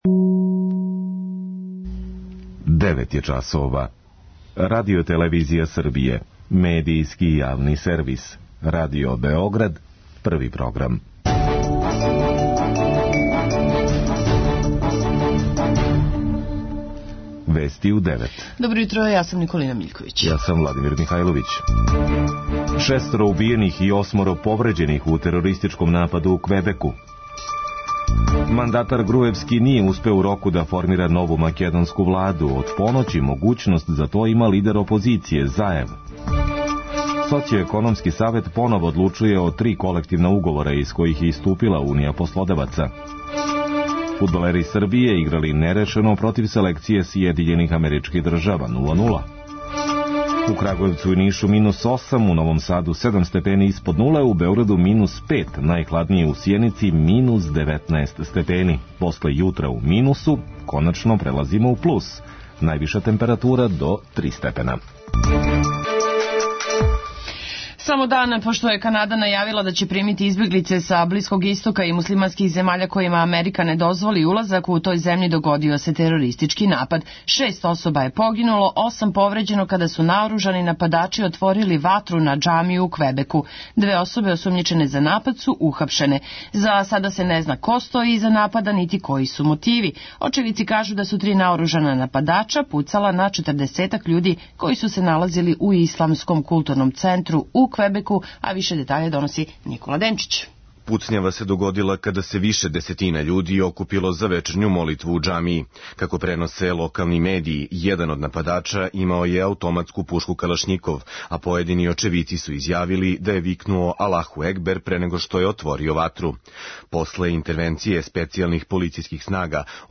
преузми : 3.89 MB Вести у 9 Autor: разни аутори Преглед најважнијиx информација из земље из света.